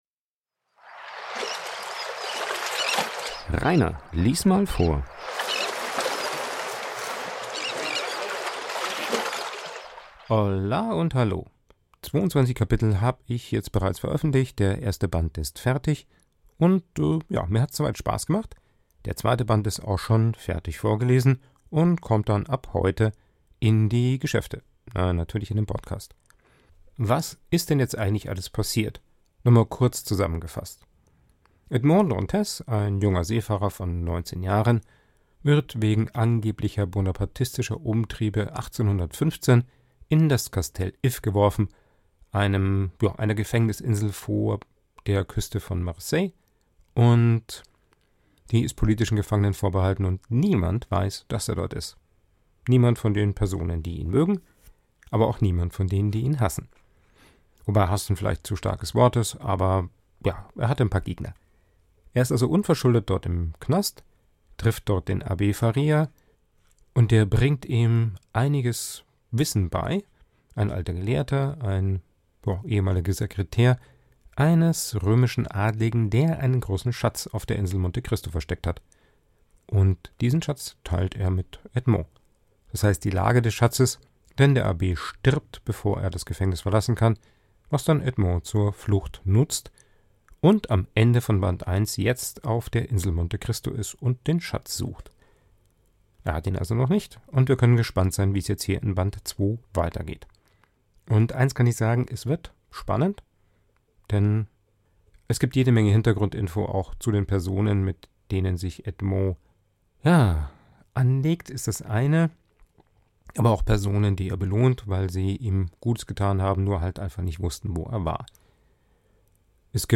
Der Vorlese Podcast